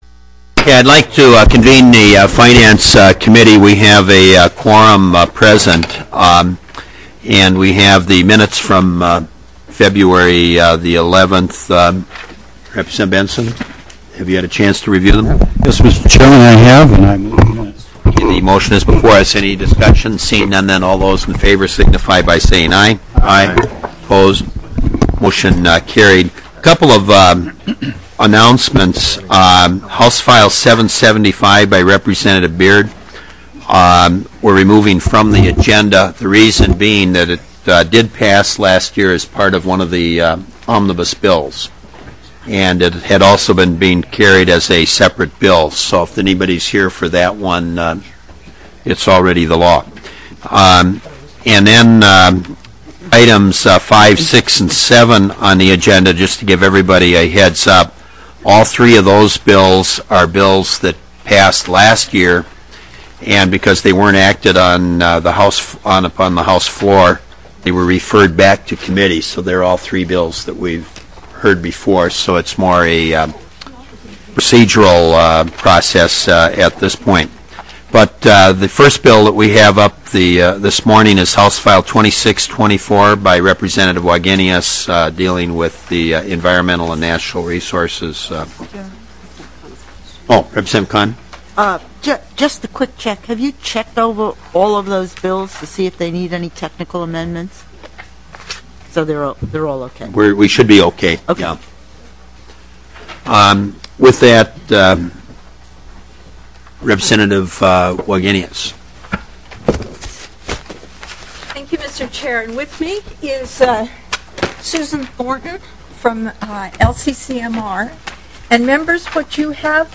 Finance 34th MEETING - Minnesota House of Representatives